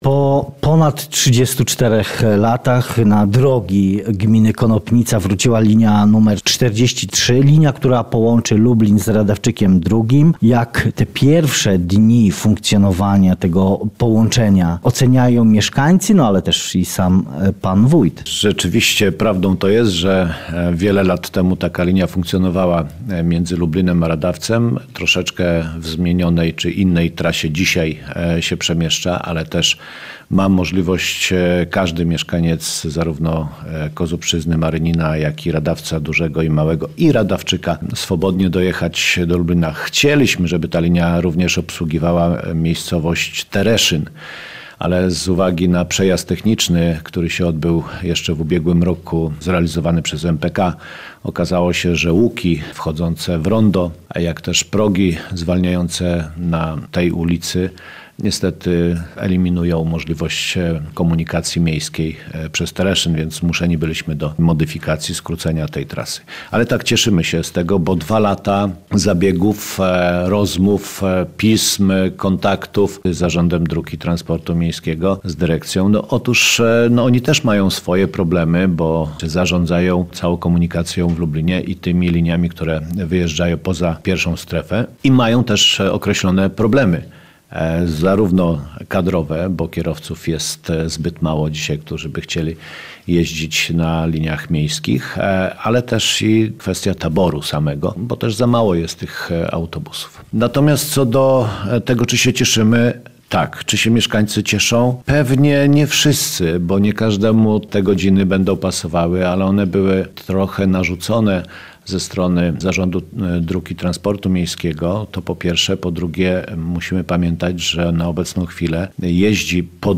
Rozmowa z wójtem Mirosławem Żydkiem